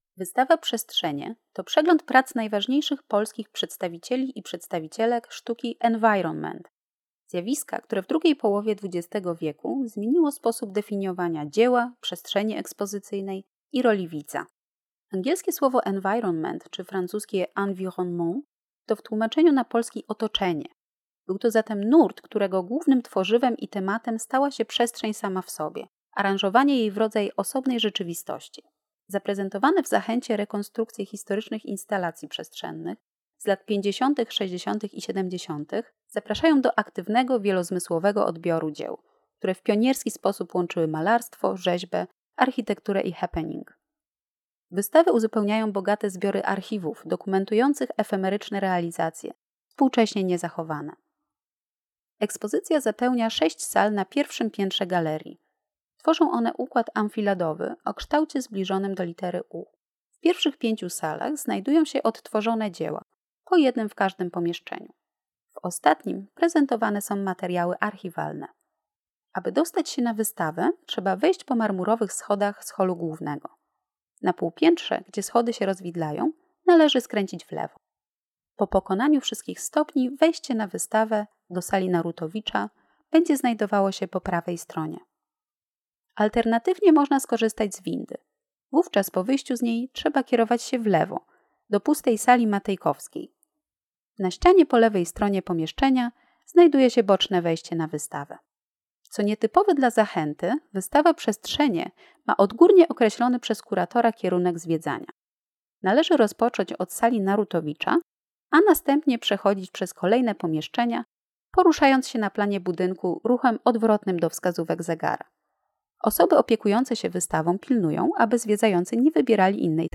Audiodescription of the exhibition: Spaces - Mediateka